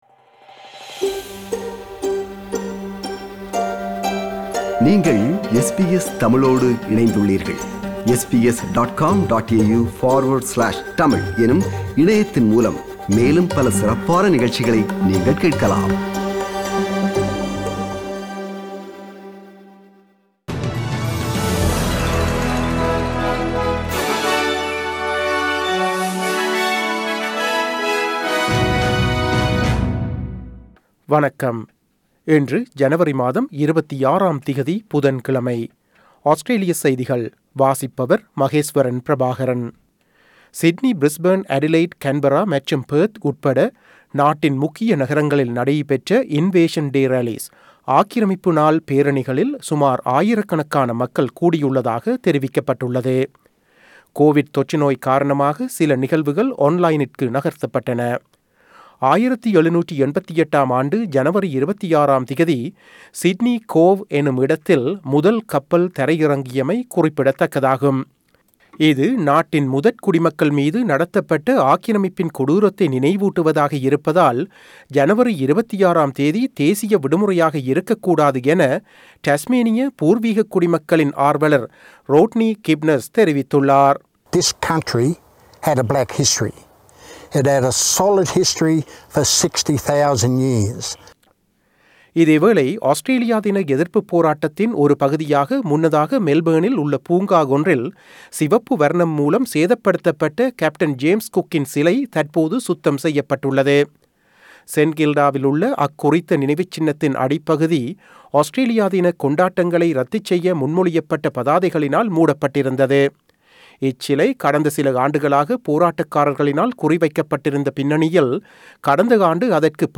Australian news bulletin for Wednesday 26 January 2022.